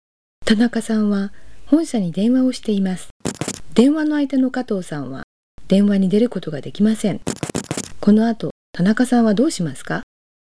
：プリクエスチョン（雑音を消去の必要あり）